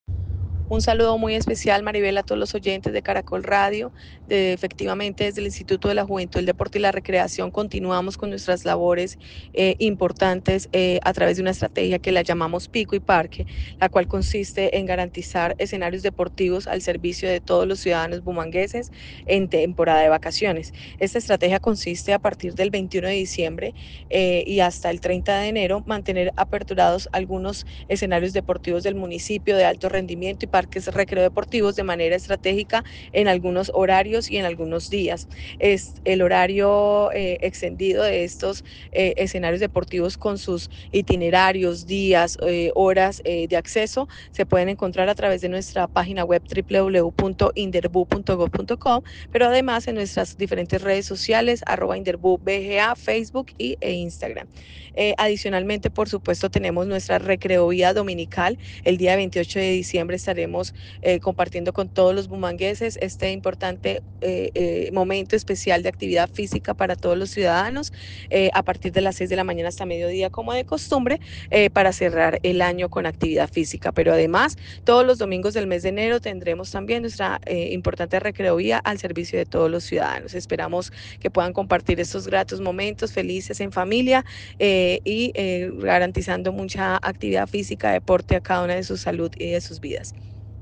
Sandra Milena Rodríguez, directora Inderbu